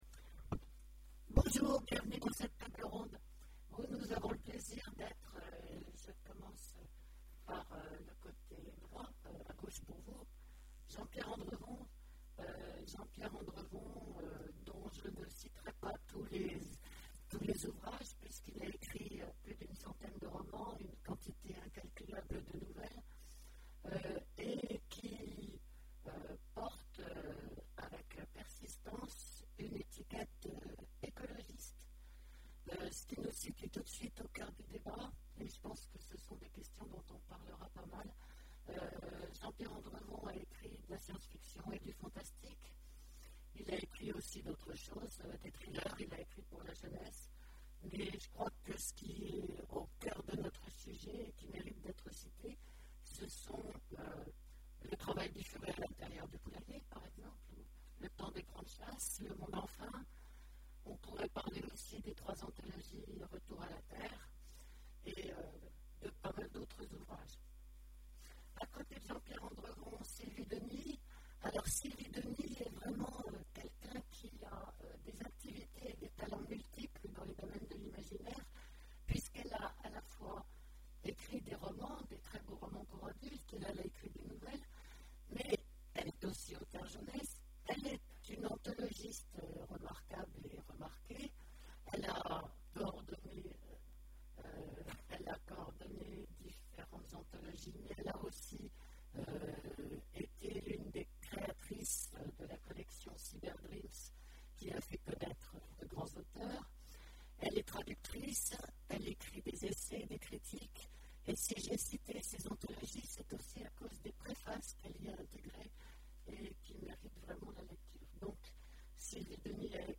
Imaginales 2014 : Conférence Retour à la terre
Conférence